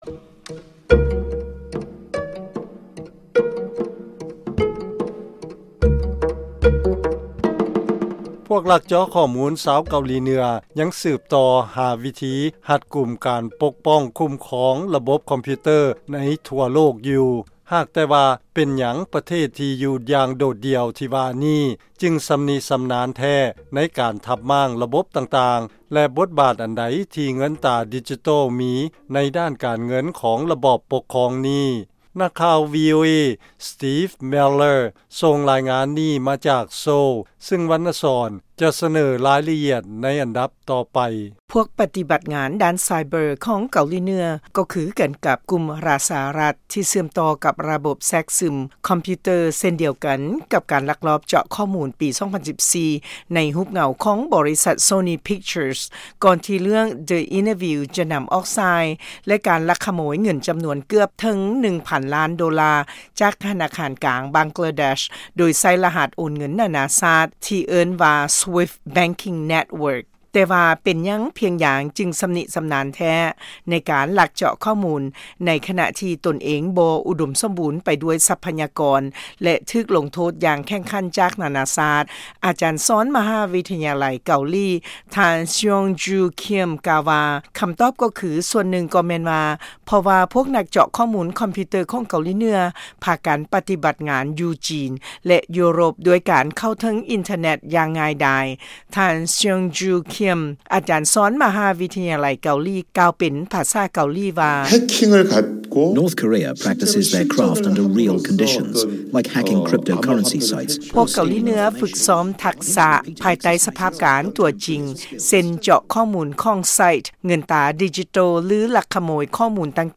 ລາຍງານກ່ຽວກັບການລັກເຈາະຂໍ້ມູນທາງໄຊເບີຣ໌ ຂອງເກົາຫຼີເໜືອ